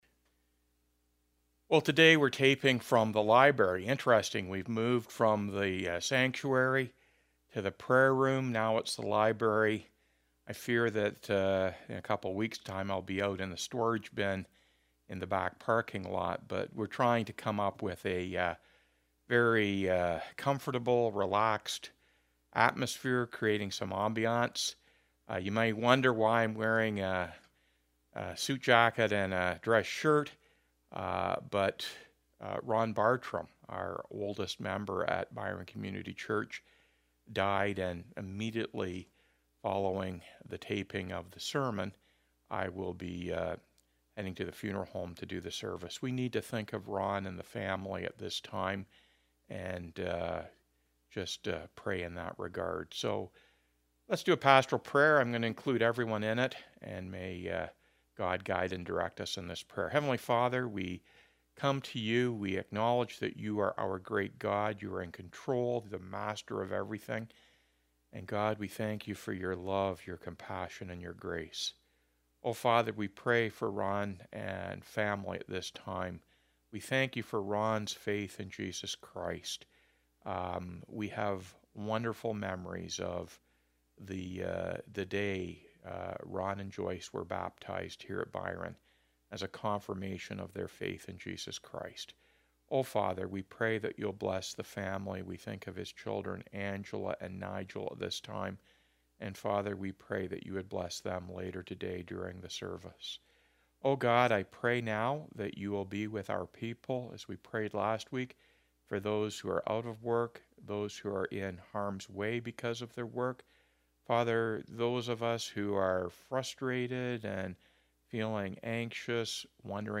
Weekly Sermons